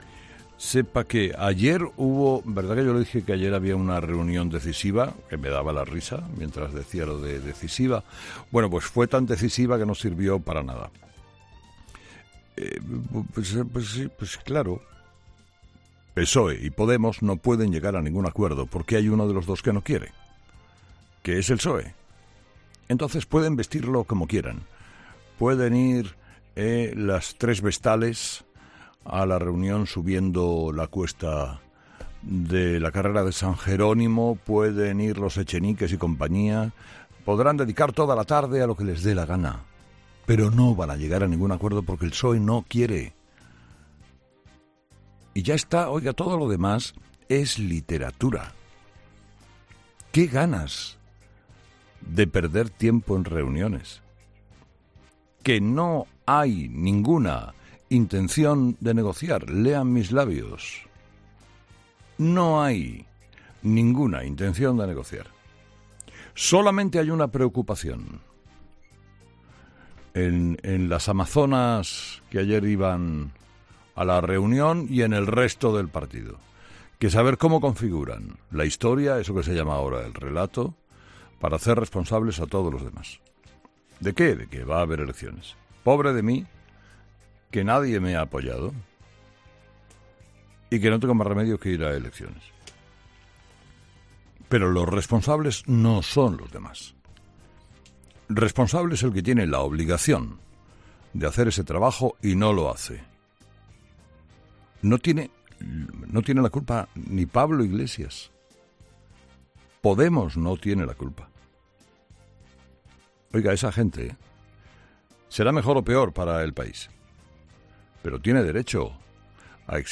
La 'decisiva' reunión mantenida entre PSOE y Unidas Podemos provoca la risa de Carlos Herrera